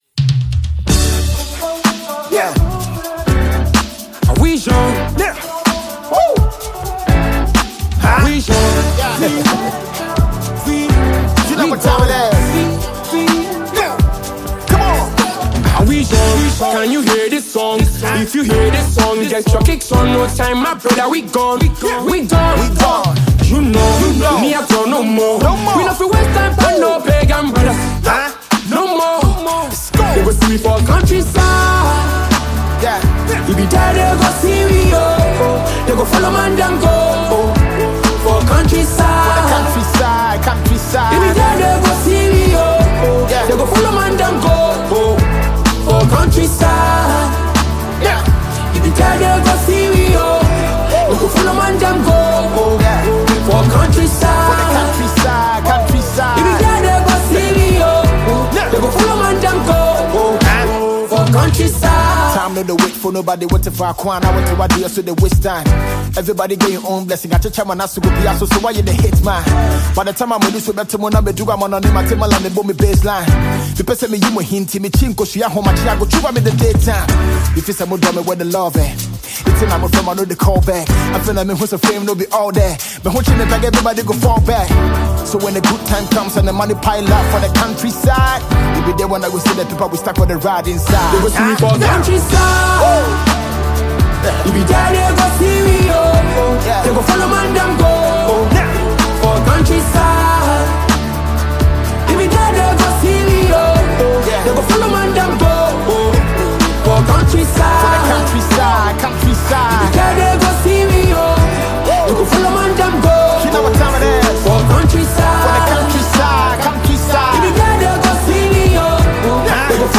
Ghanaian rap artist
catchy record